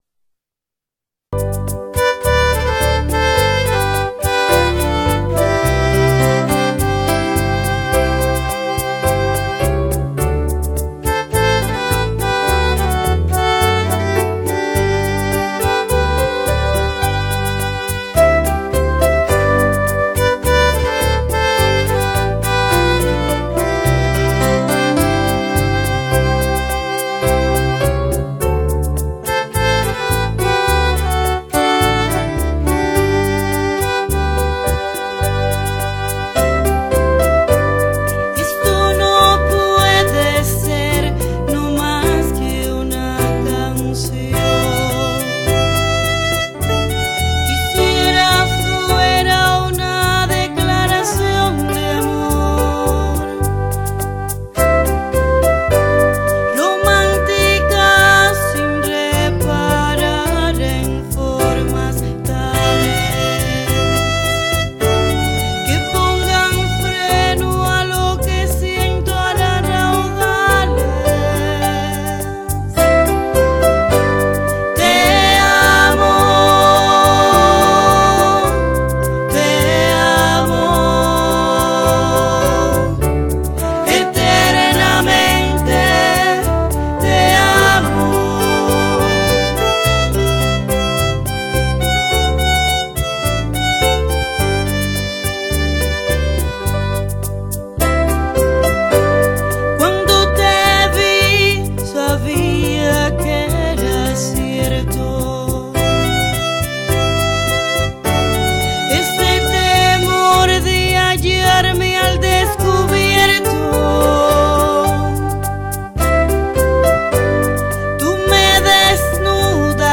Cuban band
The band Grupo Ensueño in a restaurant playing for us whilst we ate delicious pizza.